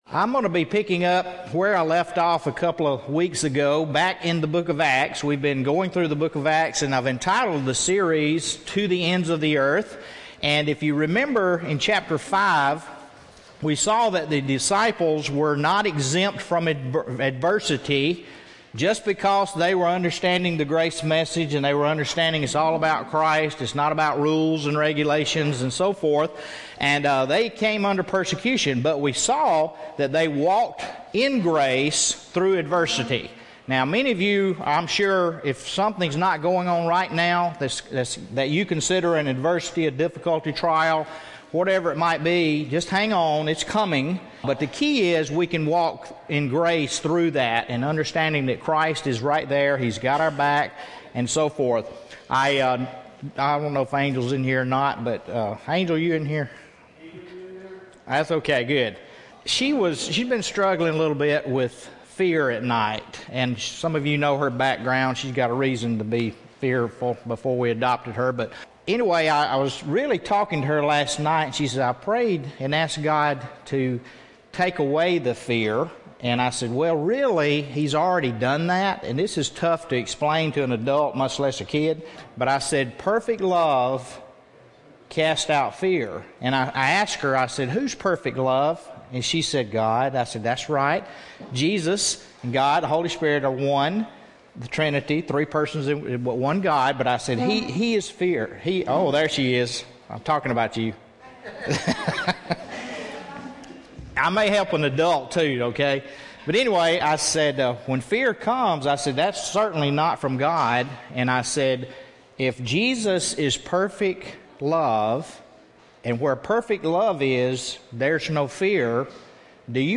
at Grace Cafe Church